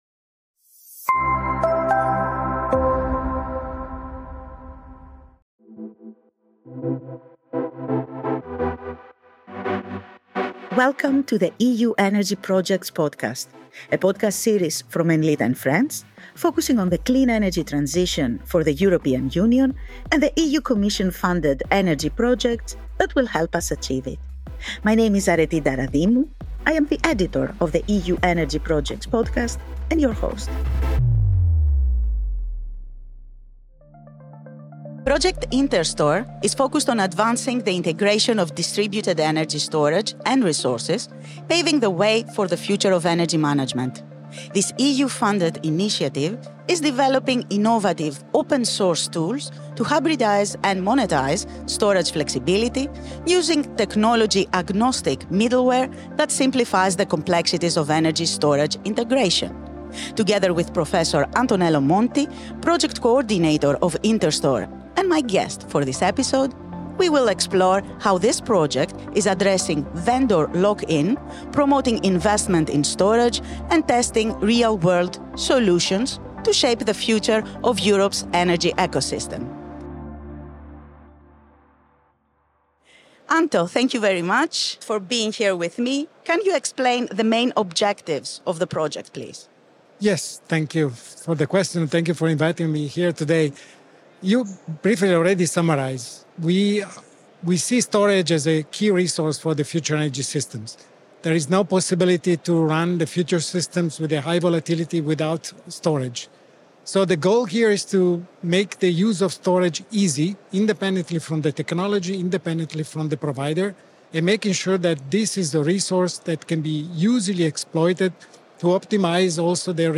The conversation also covers the significance of open-source tools and preemptive maintenance in ensuring the success and safety of these integrations.